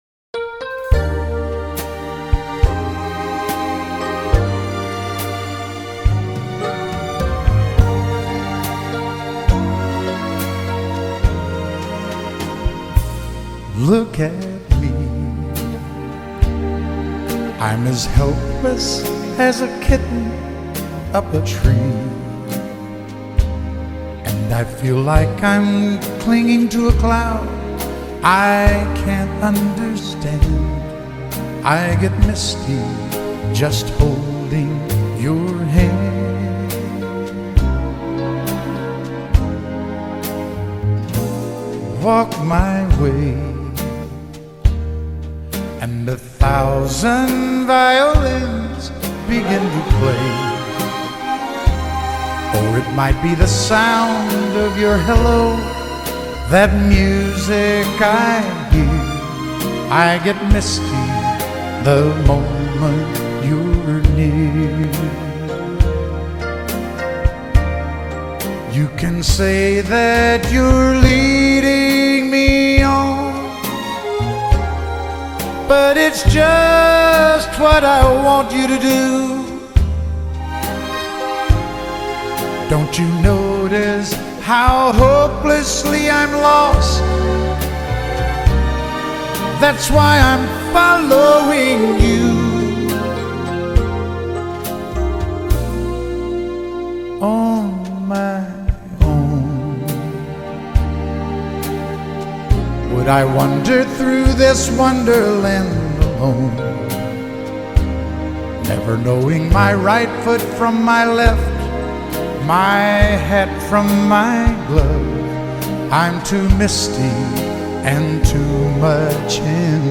Violin Tags